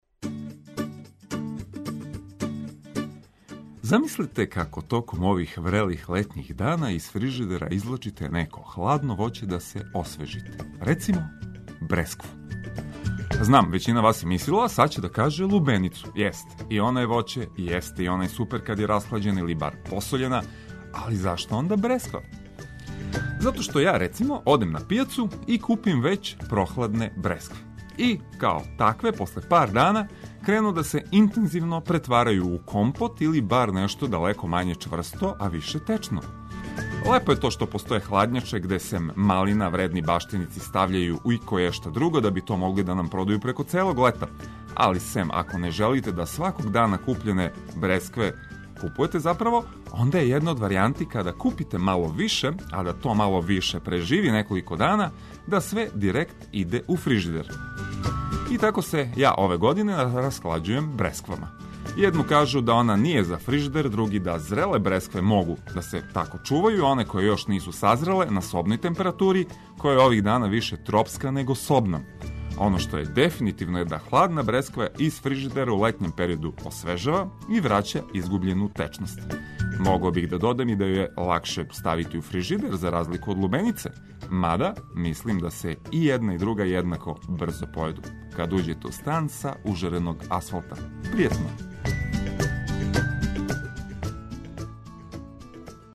Све изазове лакше превазилазимо уз корисне вести и ведру музику.